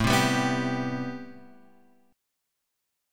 A Minor 9th